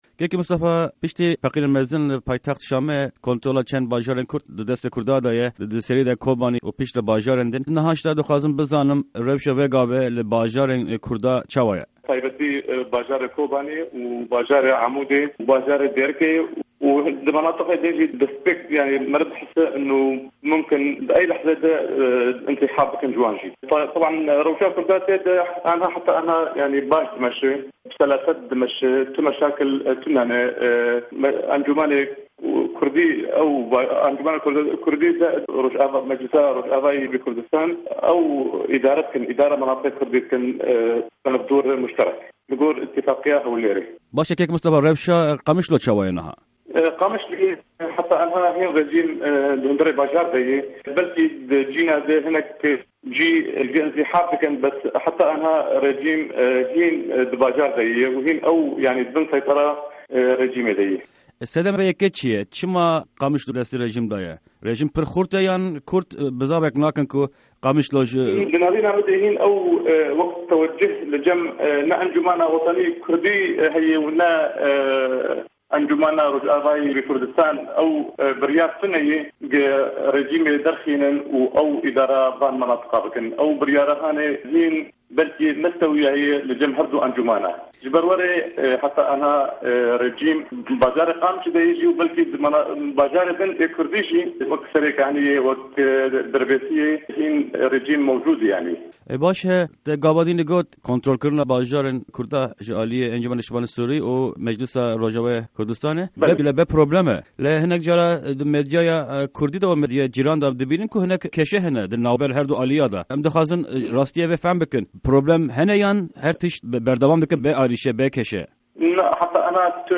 Hevpeyvîn_MO